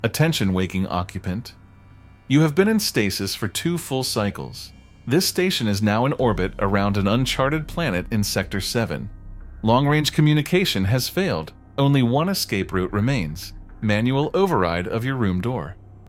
robot-voice.mp3